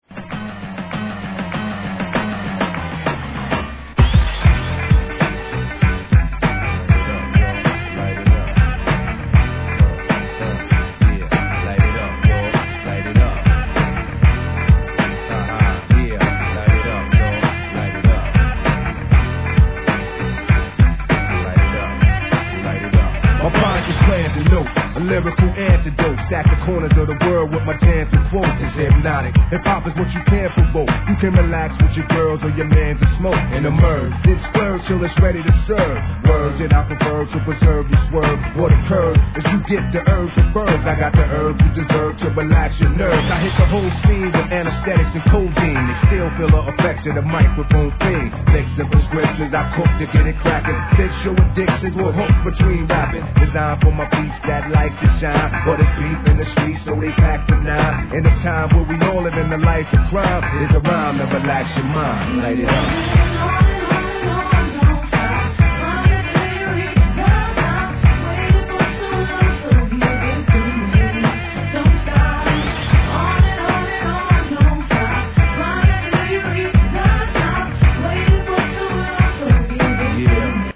HIP HOP/R&B
女性コーラスが絡むフックがいい感じ!